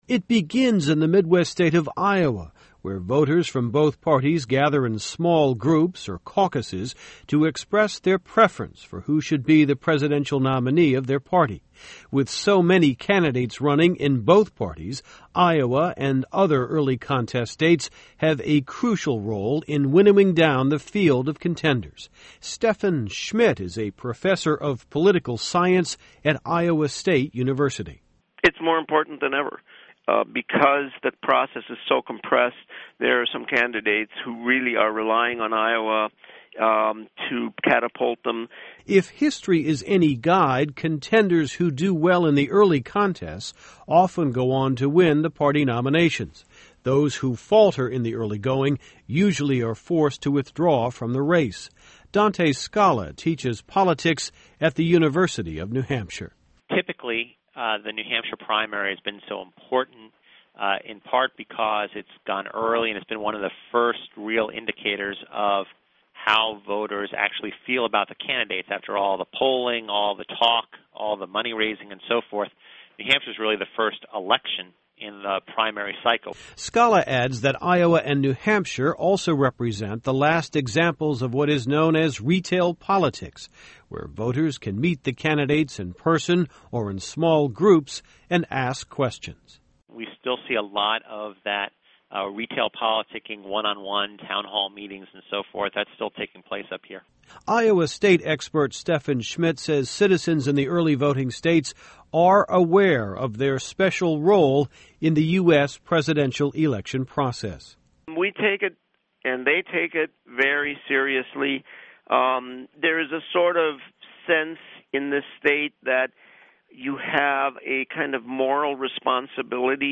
您现在的位置是：首页 > 英语听力 > VOA英语听力下载|VOA news > voa标准英语|美国之音常速英语下载|在线收听